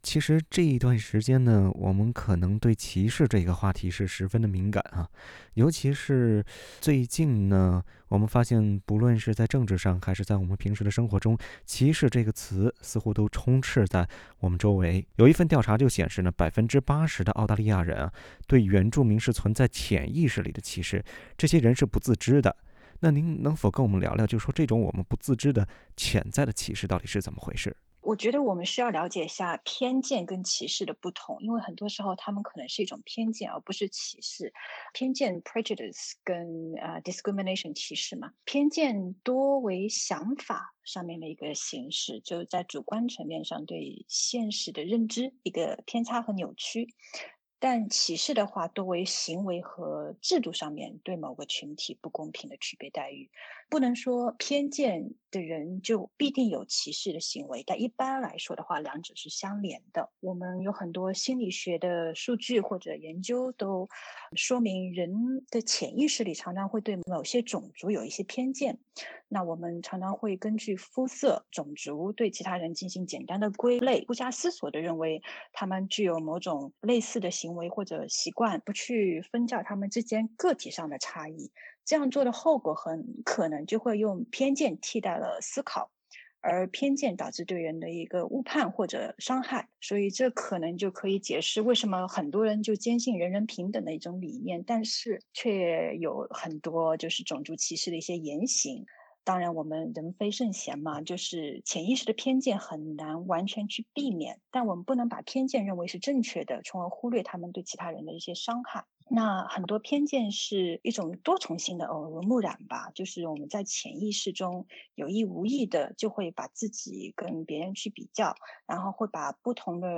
欢迎点击图片音频，收听完整采访。